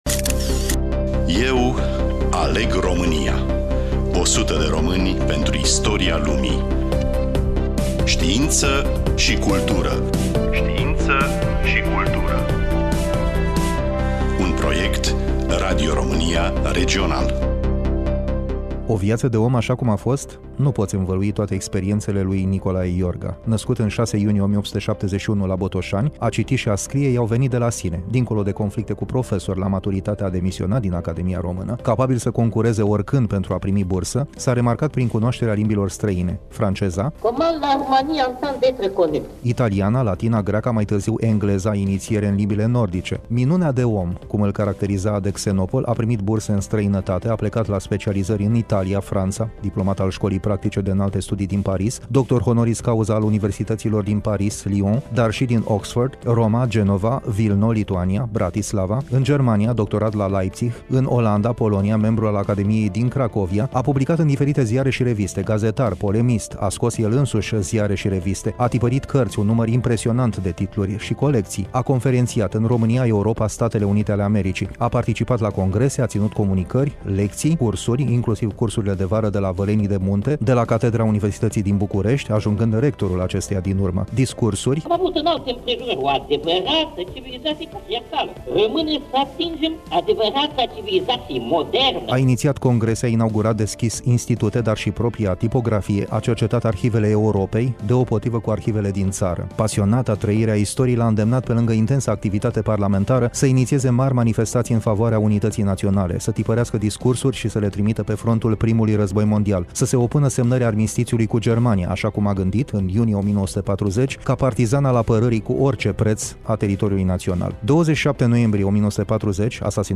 Studioul: RADIO ROMÂNIA IAȘI
Voice Over: